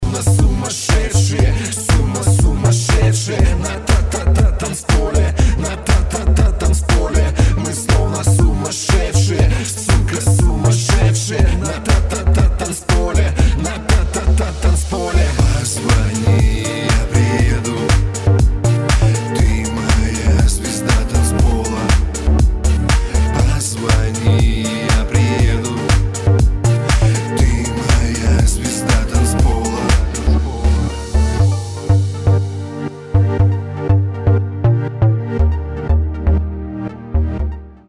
• Качество: 320, Stereo
поп
dance
Electronic
club